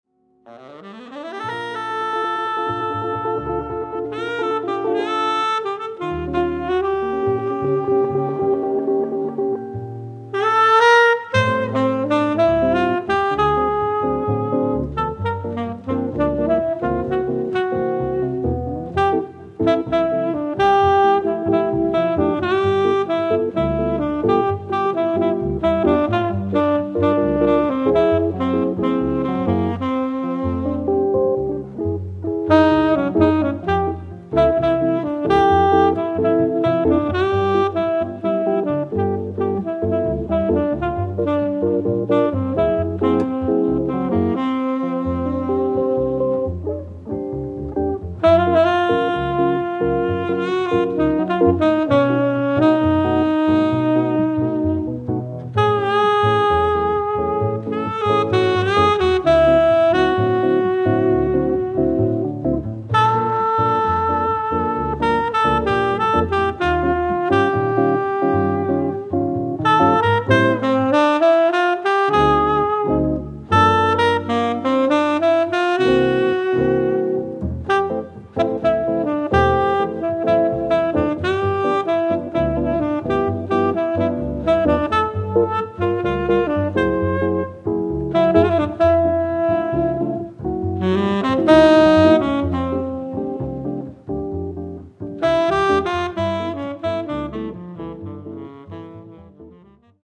Saxophon
Gitarre
Kontrabass